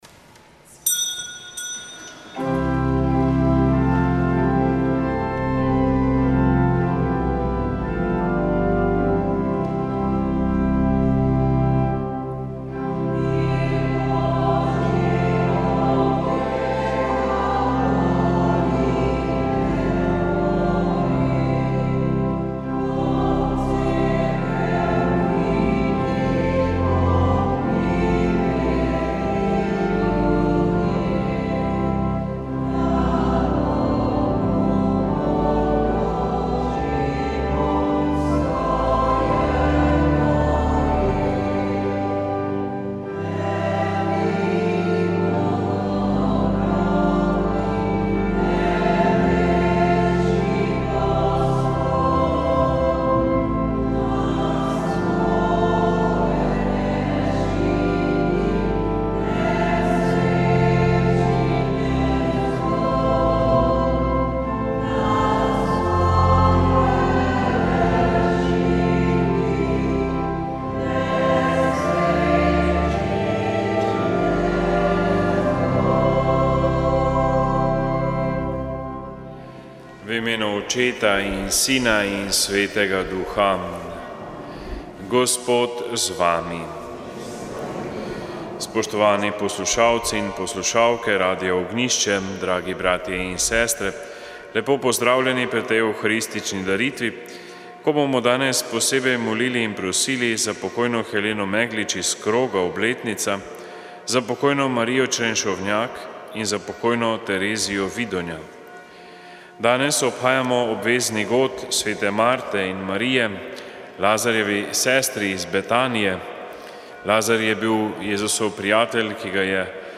Sv. maša iz cerkve Marijinega oznanjenja na Tromostovju v Ljubljani 12. 6.